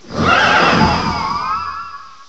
resample oversampled cries to 13379Hz